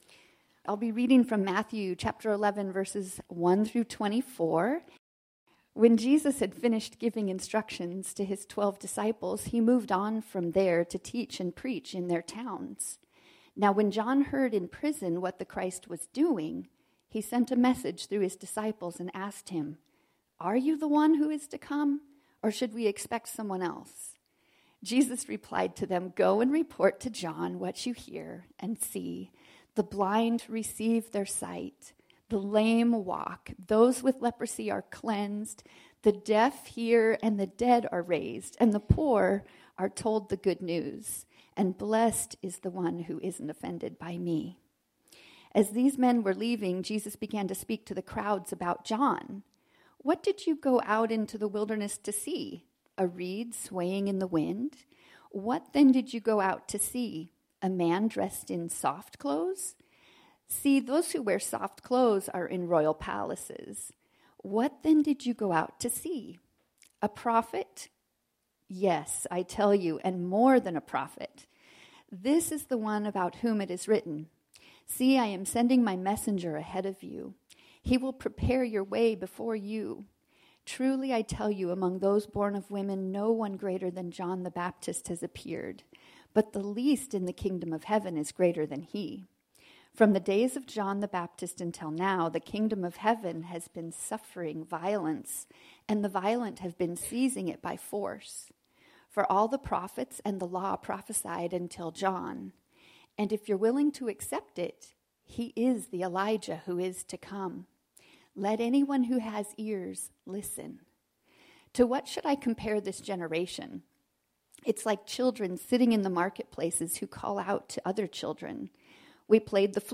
” our sermon series on the Gospel of Matthew.